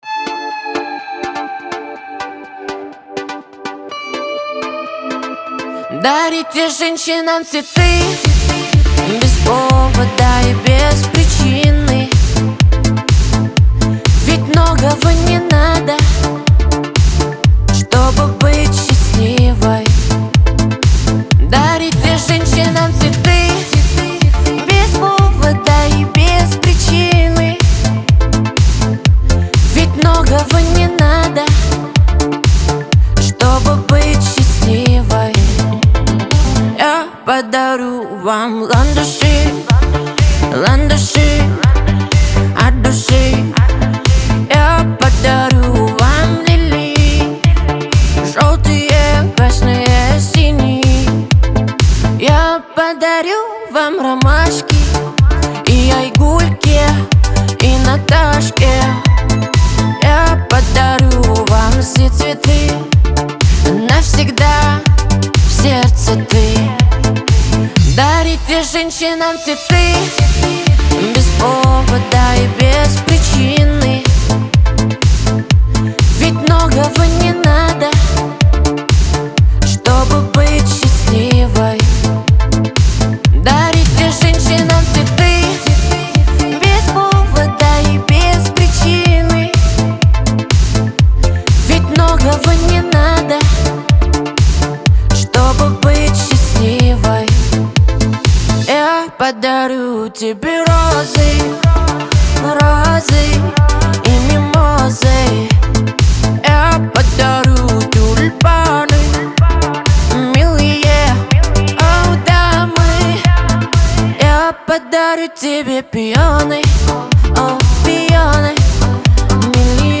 Казахские песни Слушали